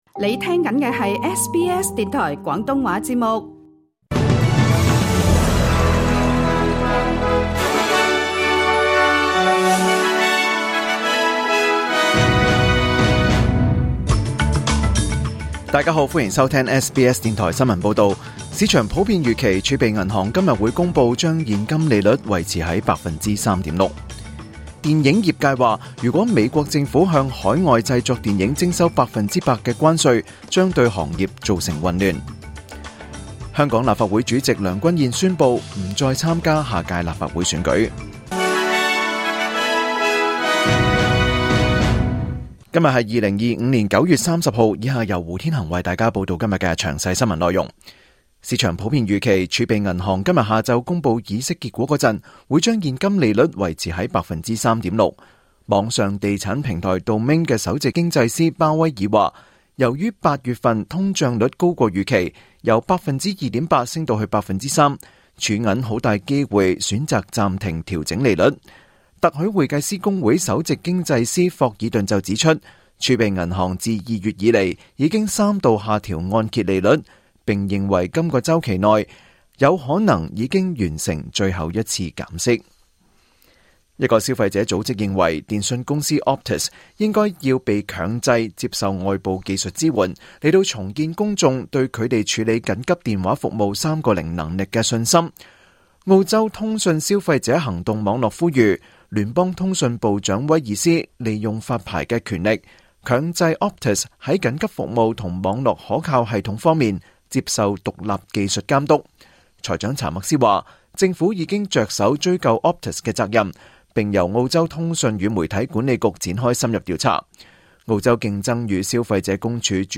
2025 年 9 月 30 日 SBS 廣東話節目詳盡早晨新聞報道。